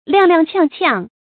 踉踉蹌蹌 注音： ㄌㄧㄤˋ ㄌㄧㄤˋ ㄑㄧㄤˋ ㄑㄧㄤˋ 讀音讀法： 意思解釋： 走路歪歪斜斜的樣子。